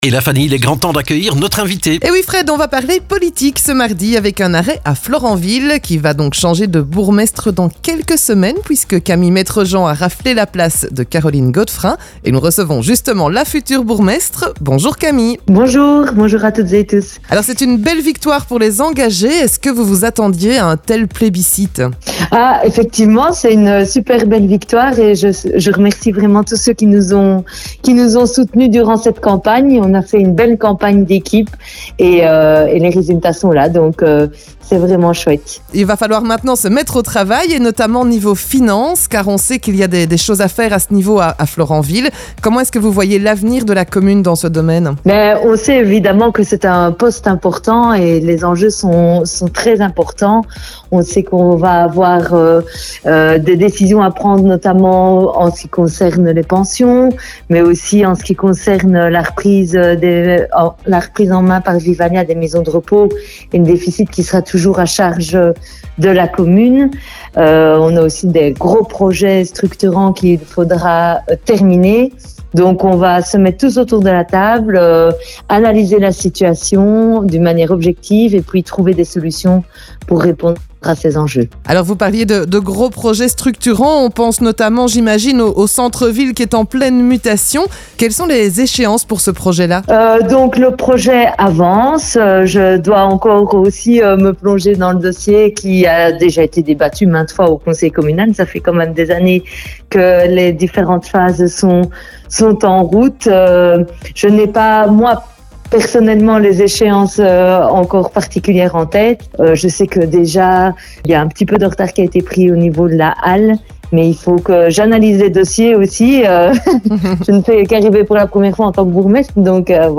La future bourgmestre de Florenville, Camille Maitrejean détaille avec nous ses priorités pour Florenville